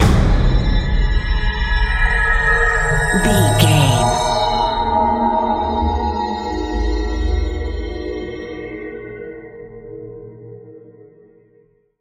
Sound Effects
Atonal
ominous
dark
eerie
drums
percussion
synthesiser